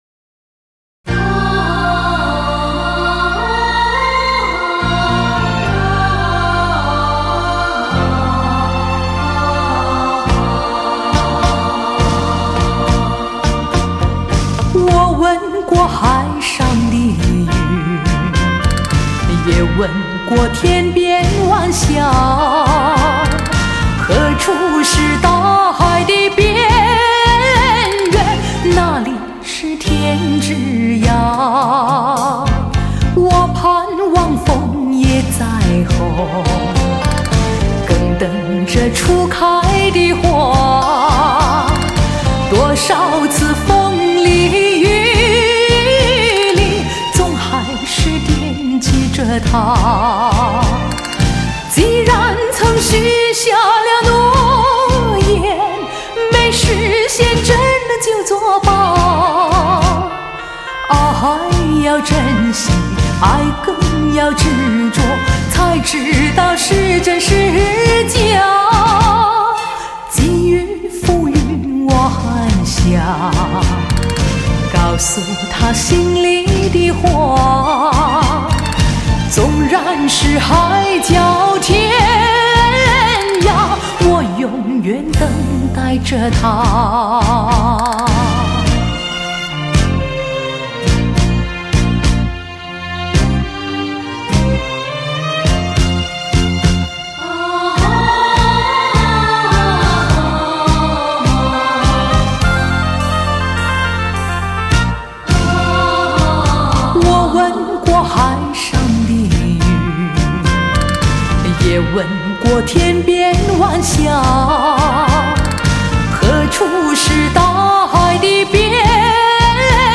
30年来最炫流行长情金曲